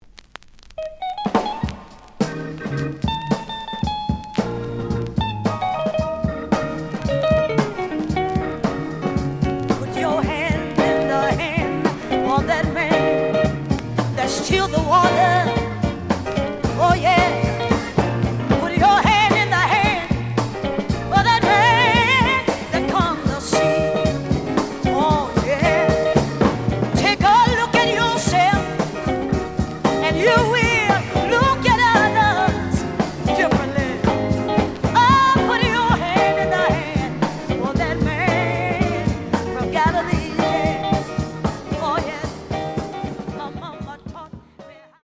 The audio sample is recorded from the actual item.
●Format: 7 inch
●Genre: Gospel